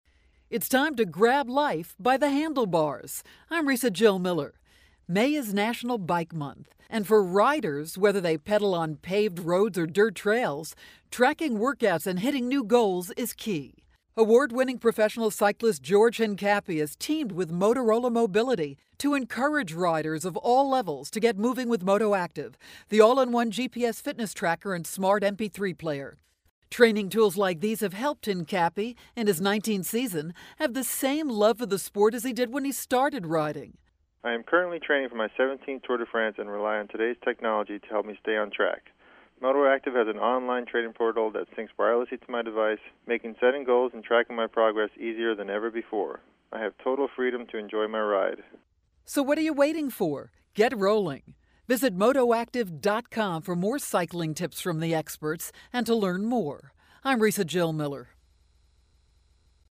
May 18, 2012Posted in: Audio News Release